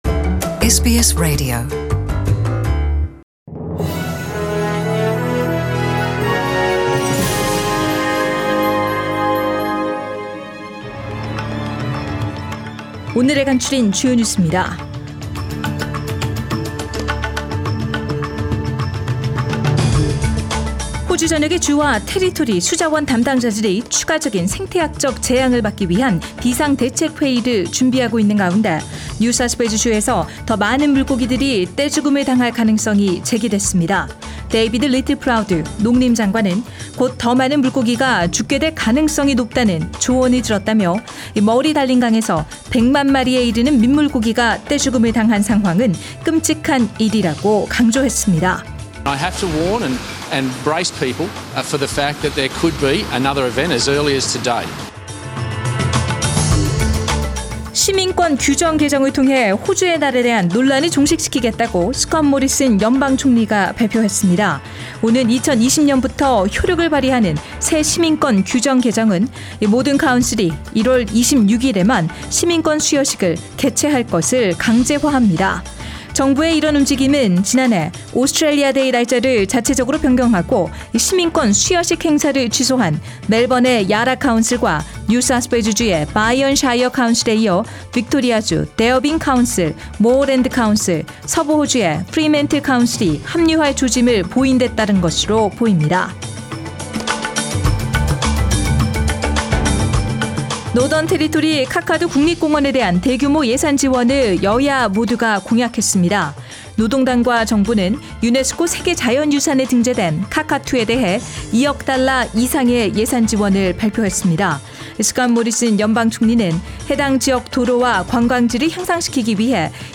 SBS Radio Korean News Bulletin Source: SBS Korean program